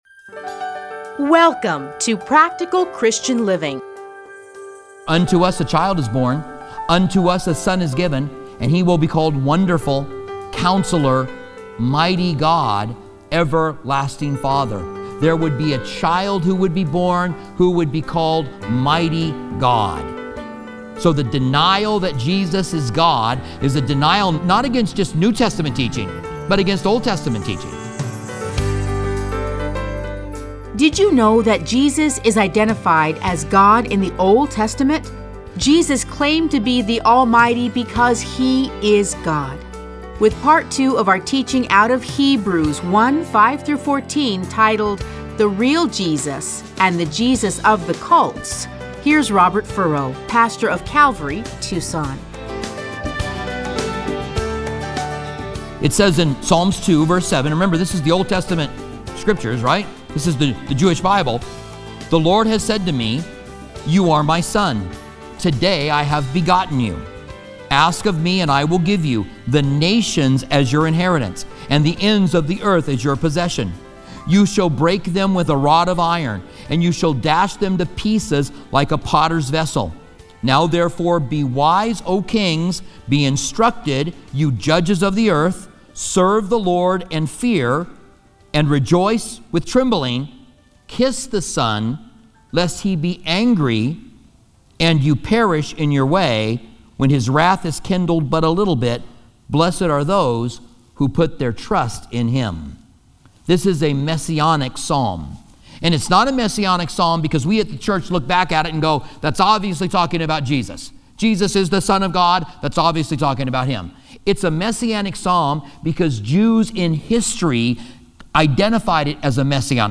Listen to a teaching from Hebrews 1:5-14.